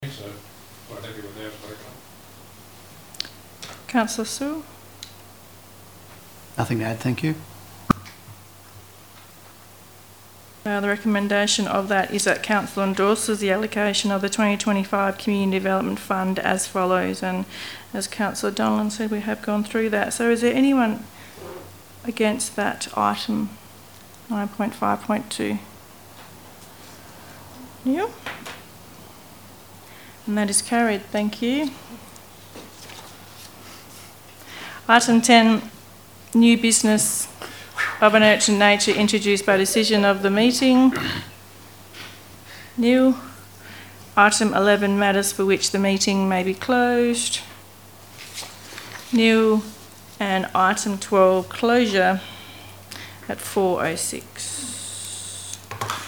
Location: Wongan Hills Shire Chambers